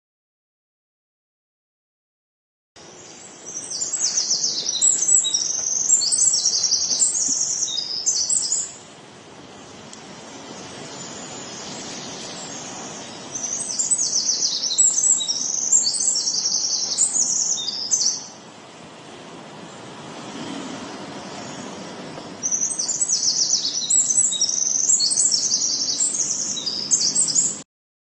林间歌者 鹪鹩鸟鸣声 小鸟唱歌